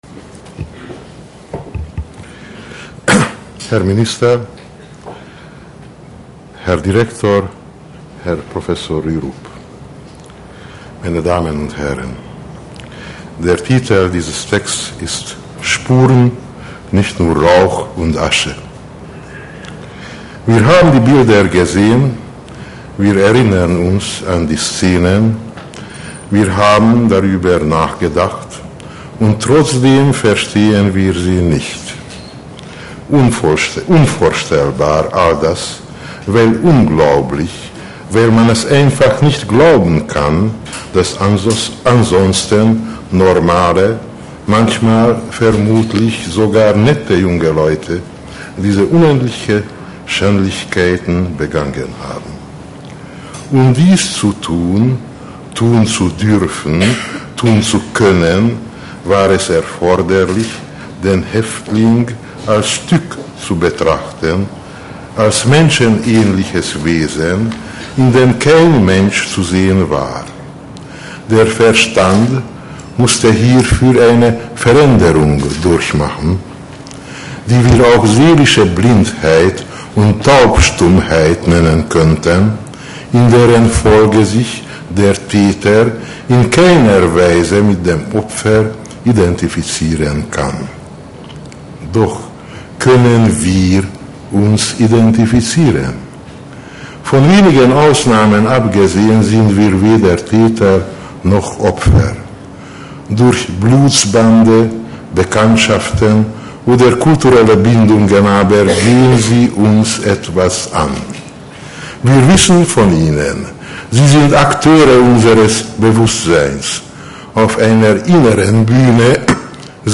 Die Ausstellung wird am Mittwoch, den 16. Januar 2002 im Kronprinzenpalais eröffnet.